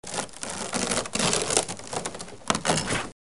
Scheggiare legno
Rumore di sfregamento legno con suono finale.